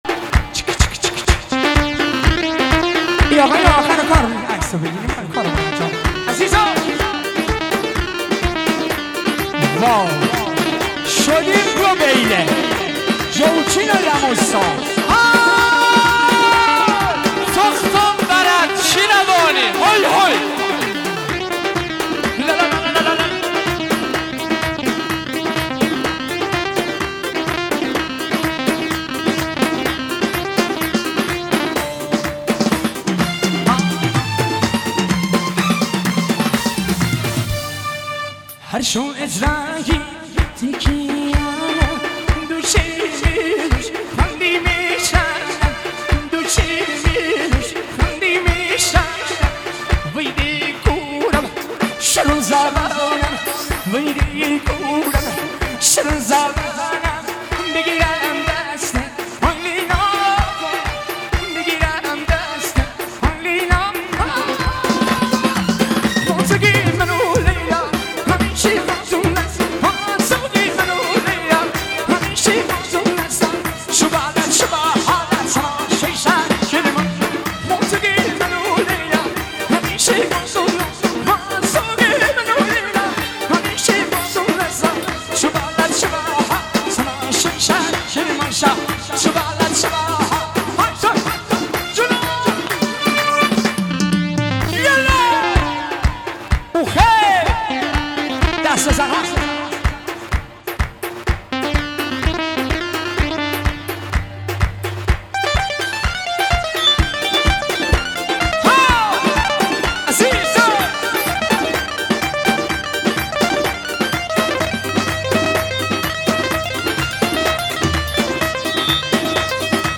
اجرای زنده در عروسی
آهنگ آمبولانسی جدید ۹۸
آهنگ ارکستری جدید